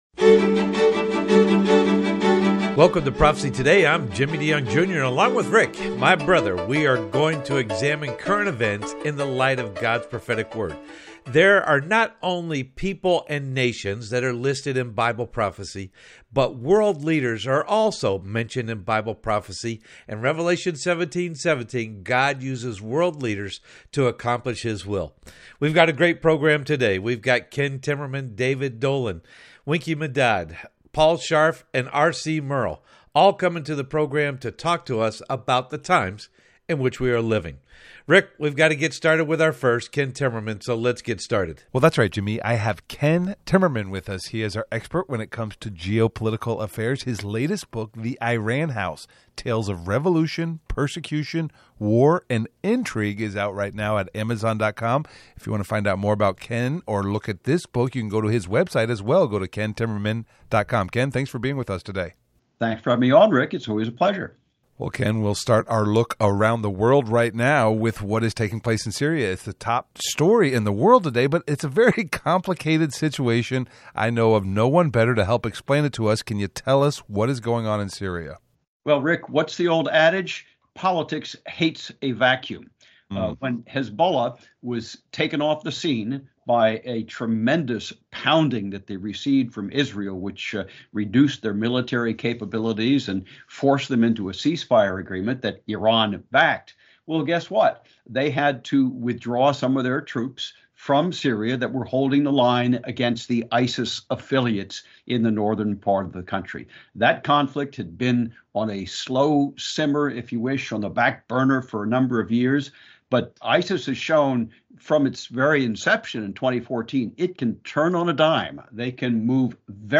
and ‘Prophecy Partners’ on the Prophecy Today Radio Broadcast heard on over 400 stations around the world…